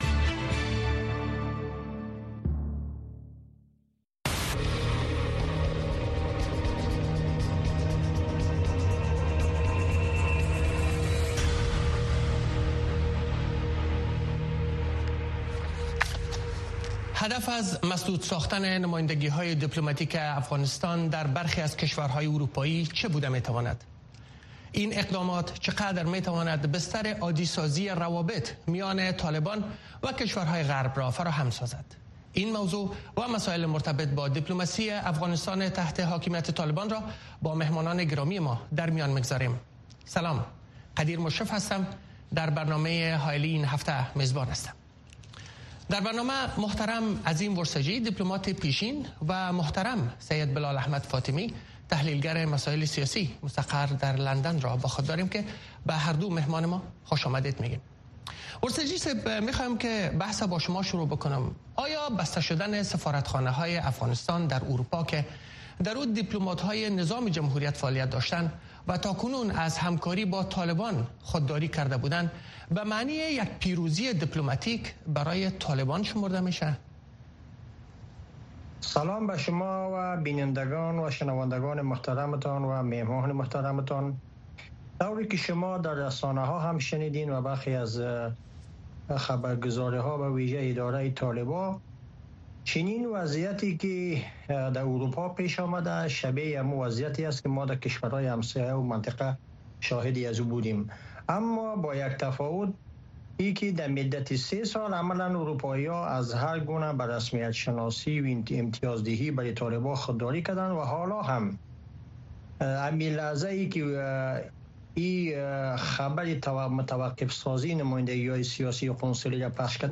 په دغه خپرونه کې د بېلابېلو اړخونو سره په مخامخ، ژورو او تودو بحثونو کې د افغانستان، سیمې، او نړۍ مهم سیاسي، امنیتي، اقتصادي، او ټولنیز موضوعات څېړل کېږي.